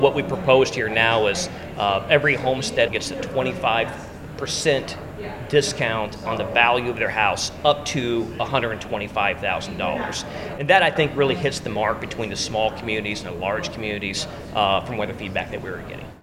Dawson discussed his latest version of property tax reform during a senate subcommittee hearing this (Wednesday) morning — and he indicated there may be more changes before the bill is debated in the full senate.